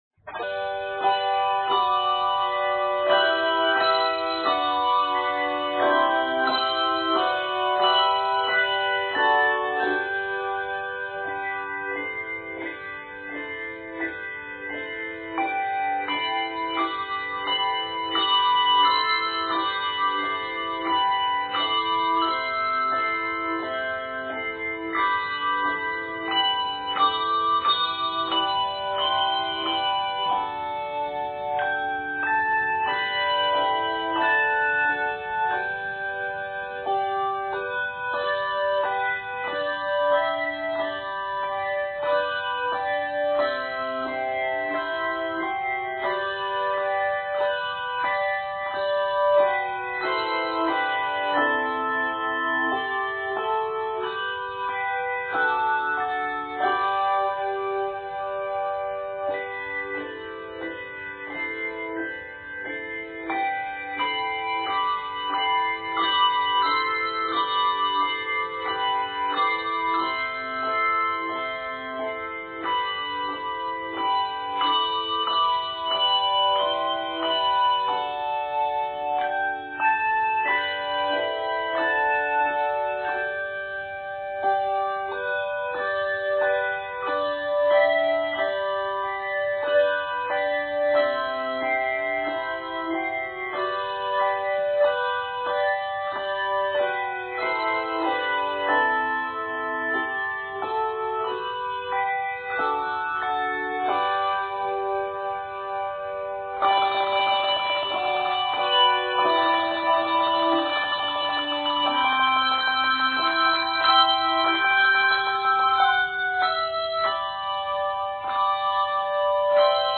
The melodies are presented one at a time and then combined.